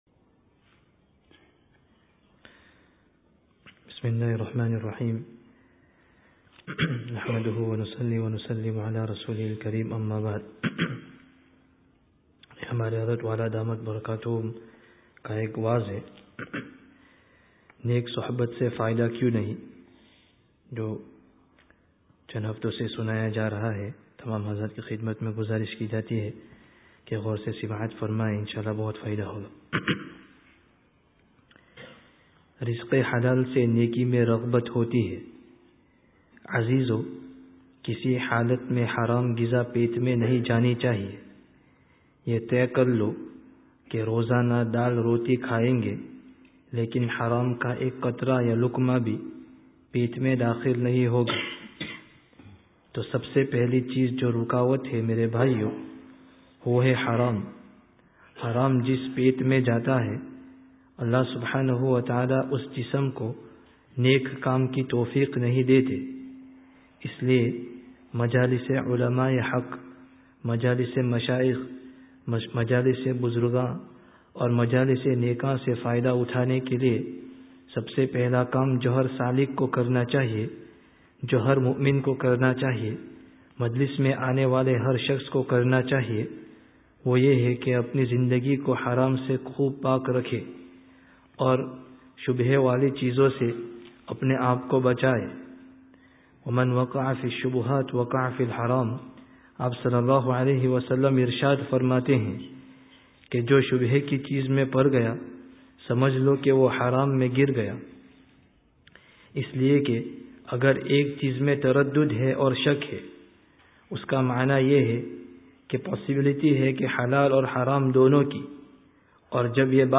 Download Urdu 2013 wa'z bil-kitab friday tazkiyah gathering Related articles Wa'z Bil-Kitāb: Neyk Suhbat se Fā'idah kyu(n) nahi(n)?